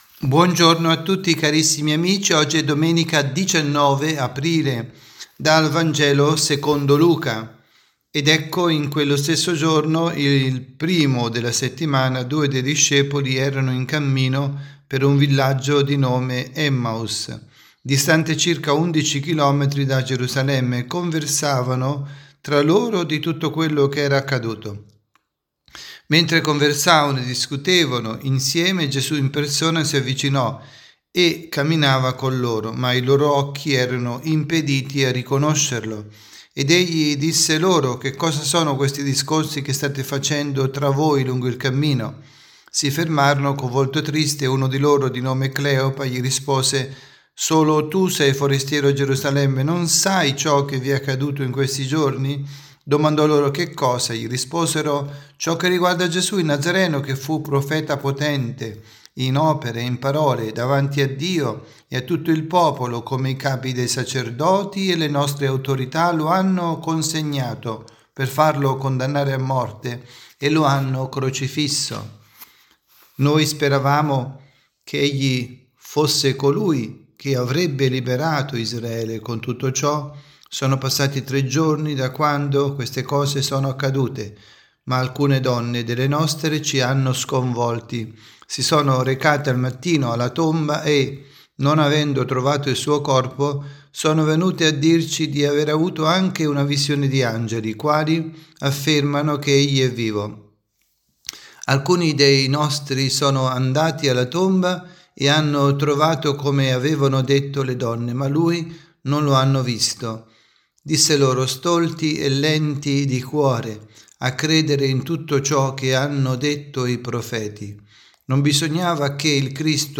avvisi, Catechesi, Omelie, Pasqua